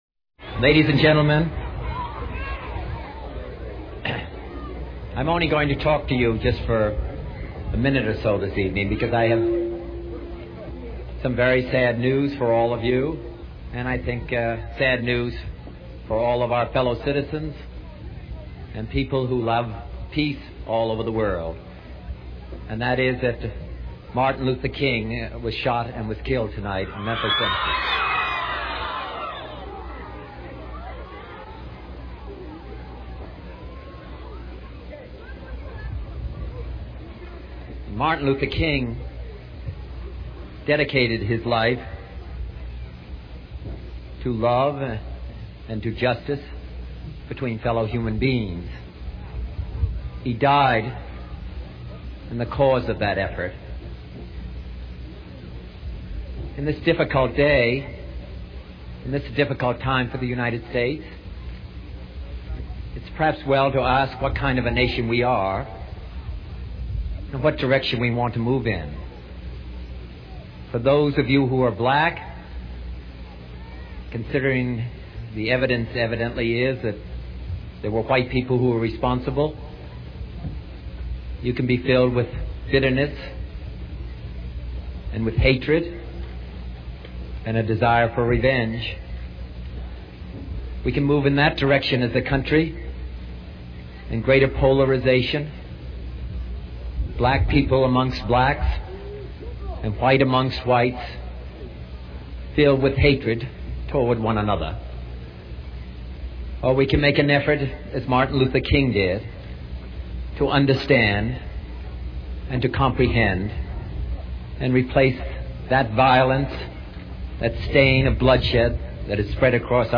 美国经典英文演讲100篇:Remarks on the Assassination of MLKing 听力文件下载—在线英语听力室